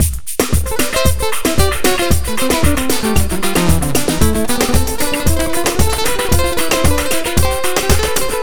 Ala Brzl 2 Full Mix 4a-A.wav